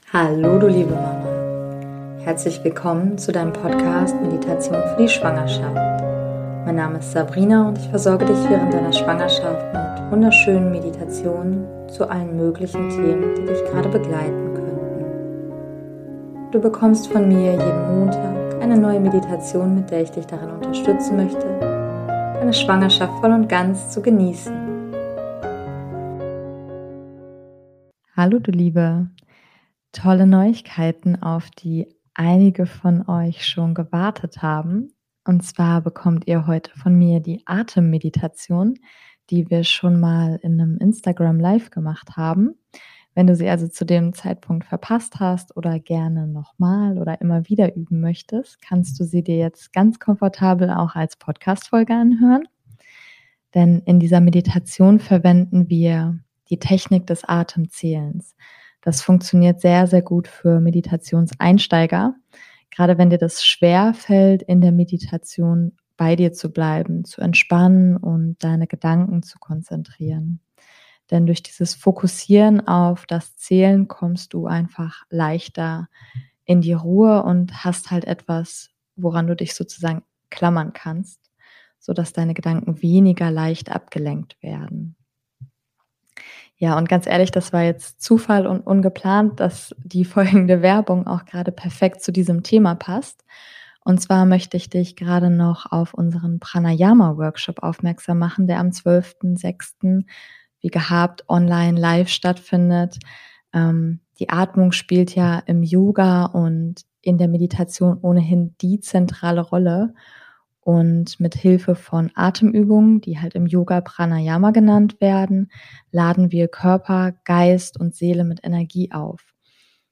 #036 - Atemmeditation für die Schwangerschaft ~ Meditationen für die Schwangerschaft und Geburt - mama.namaste Podcast
Beschreibung vor 4 Jahren Endlich bekommt ihr die Atemmeditation, die wir mal in einem Instagram live gemeinsam gemacht haben, komfortabel als Podcastfolge. In dieser Meditation verwenden wir die Technik des Atem zählens.